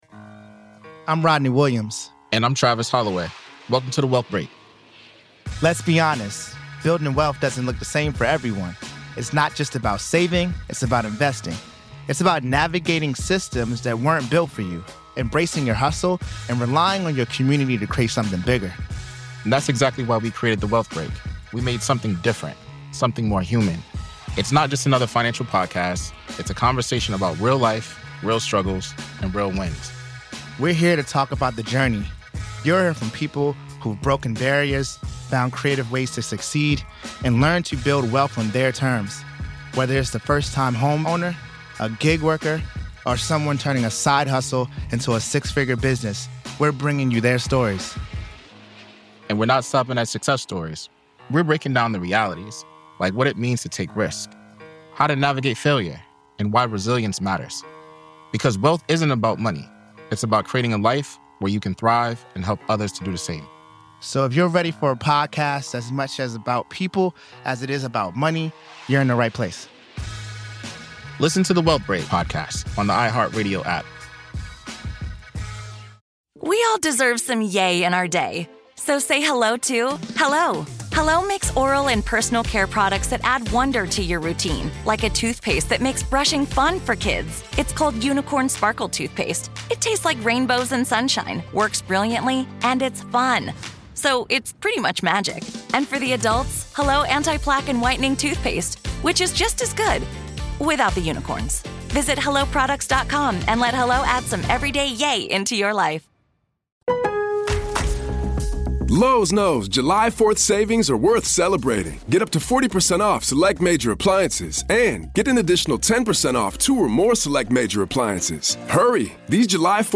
Experience the raw courtroom drama firsthand as we delve into the "Rust" movie shooting trial with unfiltered audio and expert analysis.
Go beyond the headlines: Hear the emotional pleas and heated arguments directly from the courtroom. Gain insider insights from legal experts as they break down the complex charges, the defense strategy, and the potential impact on the industry.